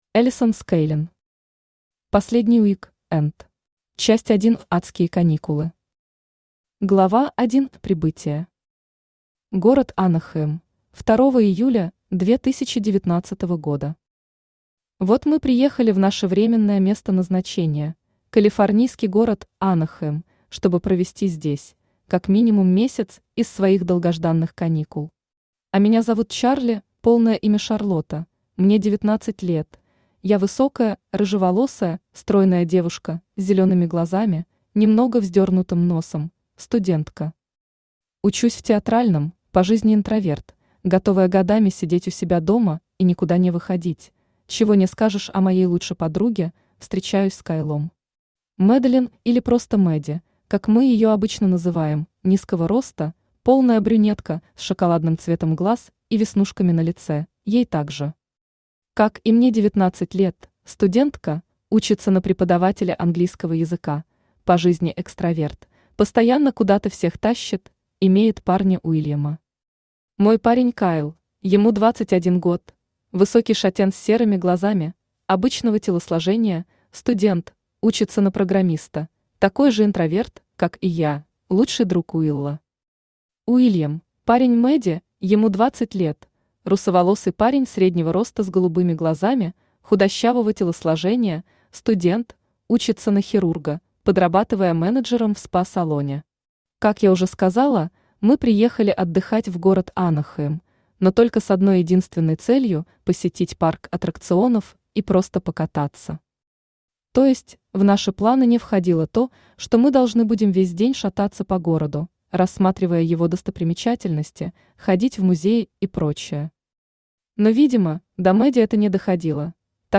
Aудиокнига Последний уик-энд Автор Alison Skaling Читает аудиокнигу Авточтец ЛитРес.